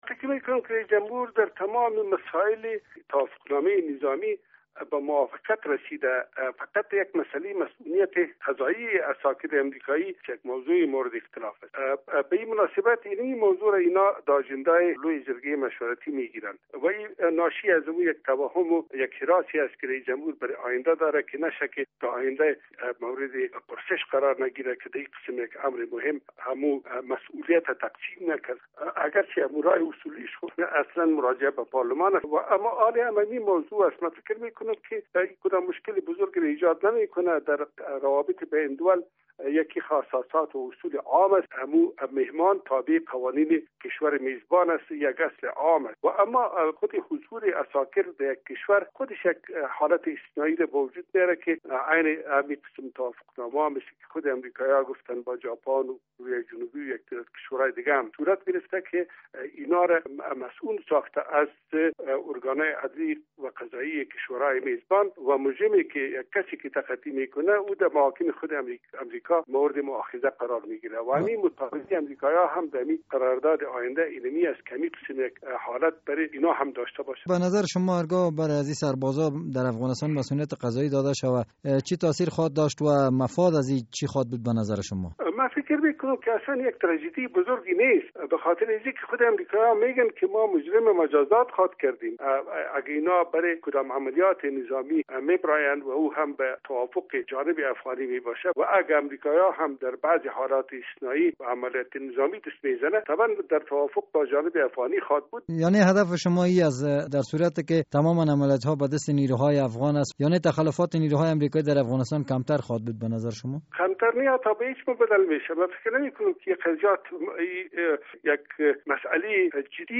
مصاحبه با کبیر رنجبر در مورد دادن مصونیت قضایی به عساکر امریکایی